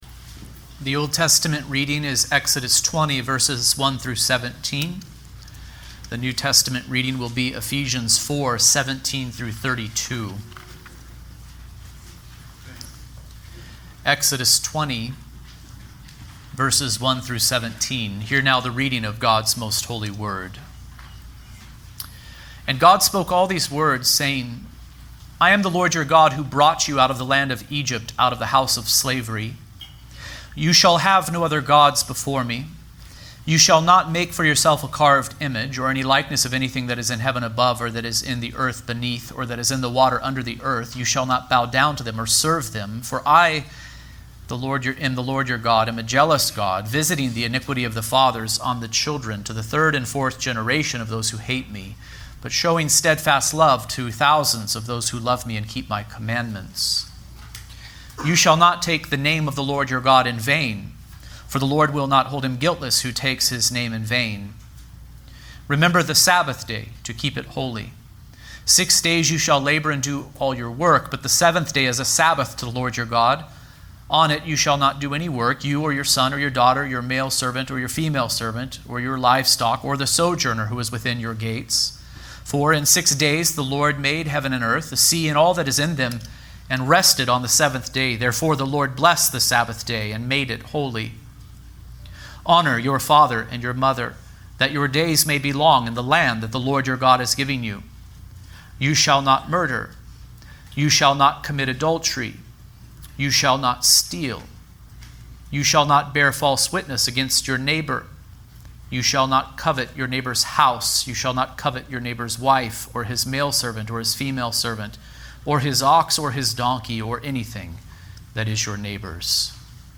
The Eighth Commandment | SermonAudio Broadcaster is Live View the Live Stream Share this sermon Disabled by adblocker Copy URL Copied!